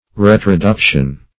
Search Result for " retroduction" : The Collaborative International Dictionary of English v.0.48: Retroduction \Re`tro*duc"tion\, n. [L. retroducere, retroductum, to lead or bring back; retro backward + ducere to lead.]